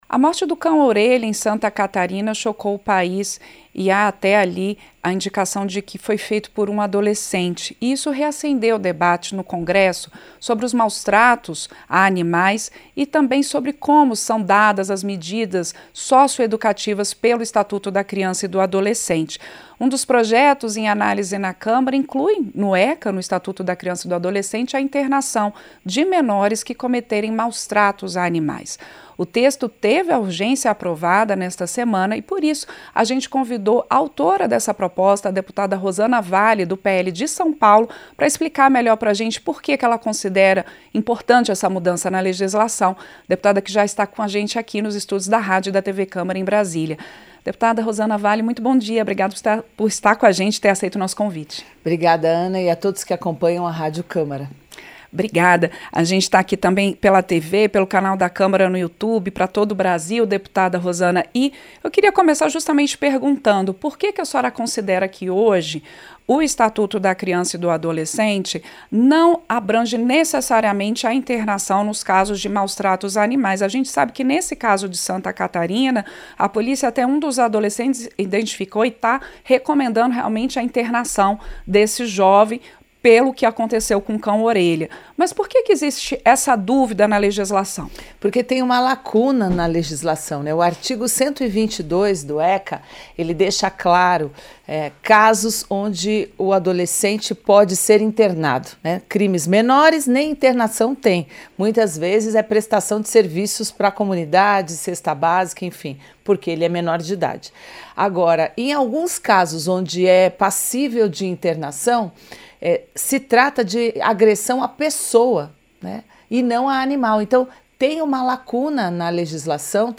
Entrevista - Dep. Rosana Valle (PL-SP)